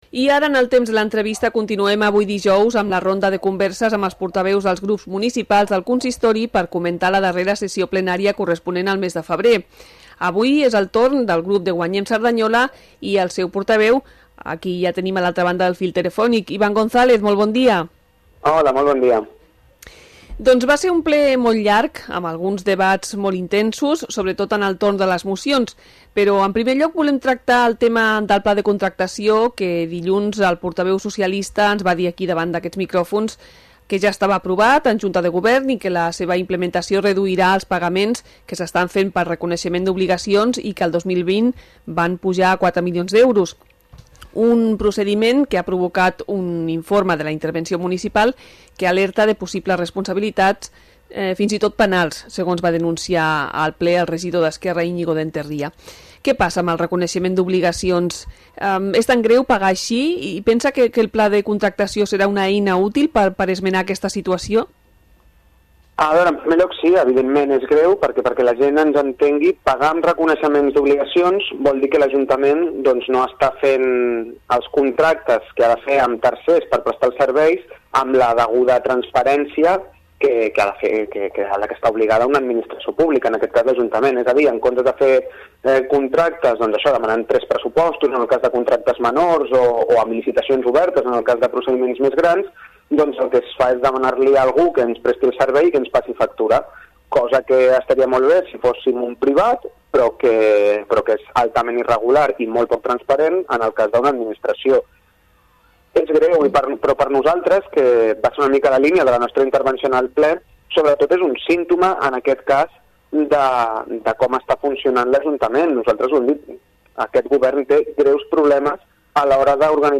Entrevista-Ivan-González-Guanyem-Ple-febrer.mp3